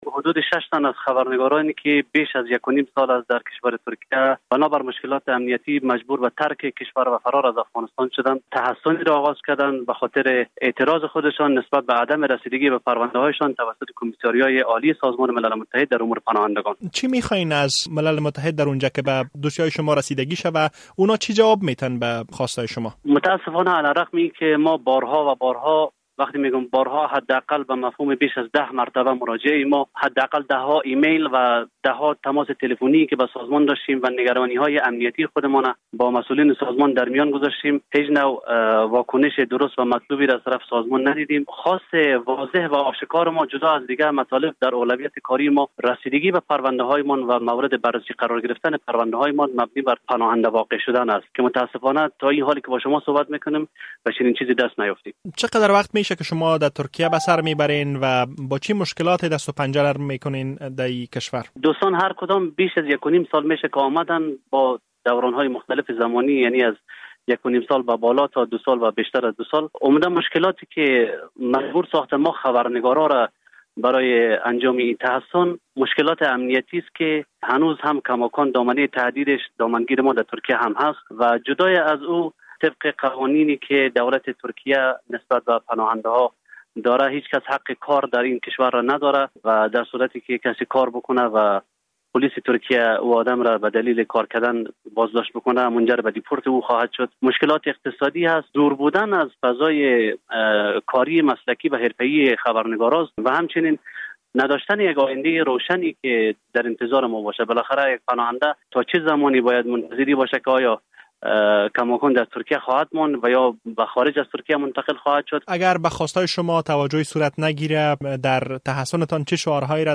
مصاحبه - صدا
مصاحبه